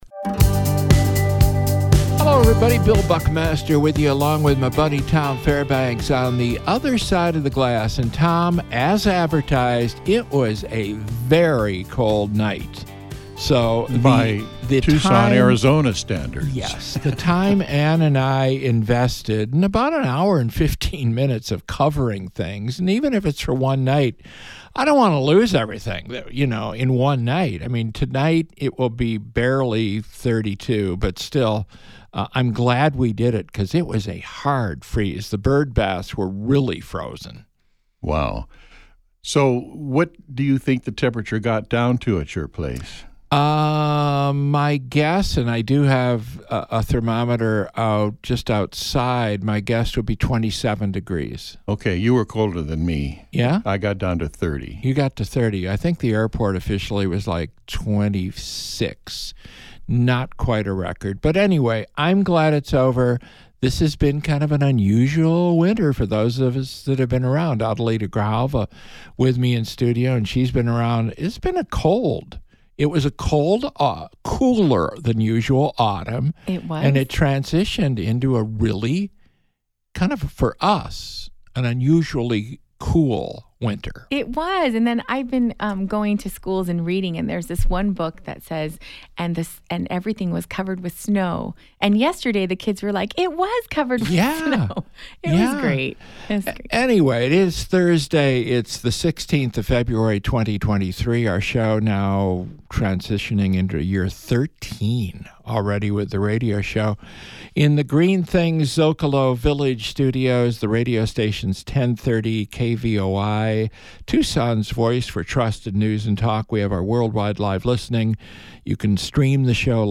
Guests include Adelita Grijalva, Chair, Pima County Board of Supervisors